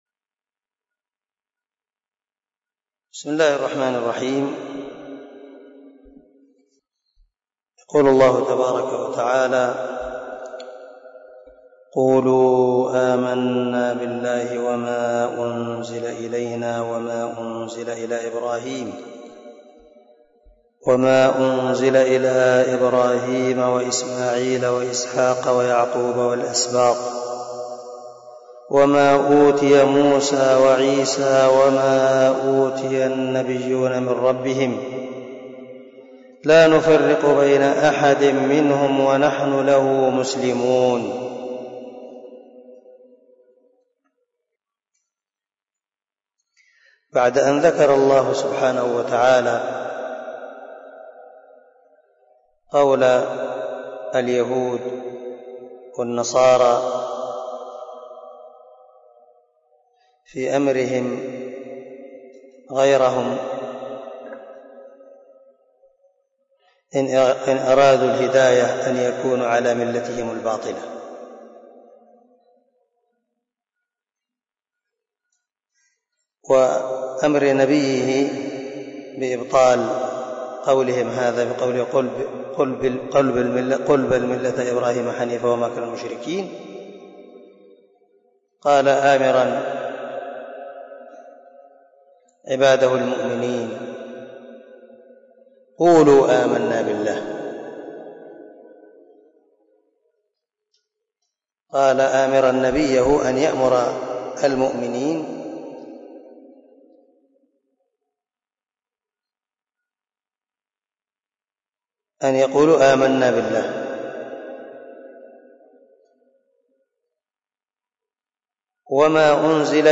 056الدرس 46 تفسير آية ( 136 ) من سورة البقرة من تفسير القران الكريم مع قراءة لتفسير السعدي
دار الحديث- المَحاوِلة- الصبيحة.